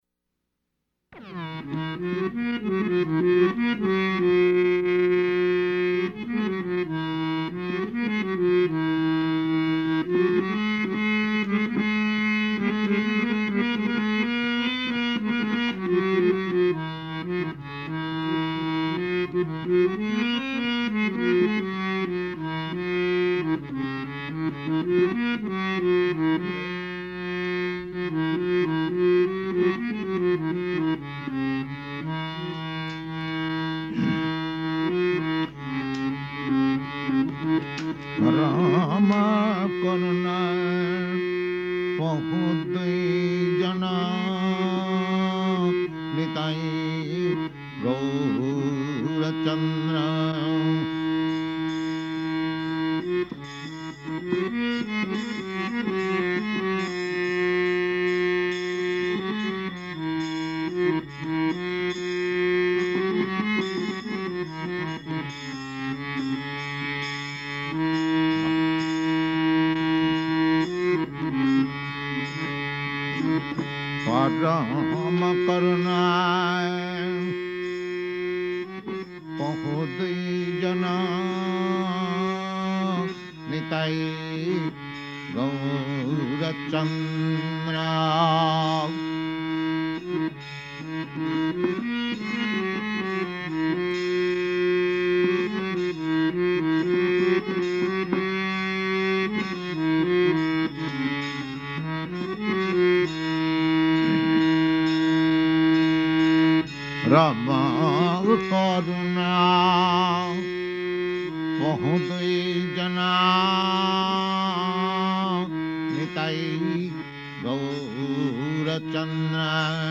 Type: Purport
Location: Los Angeles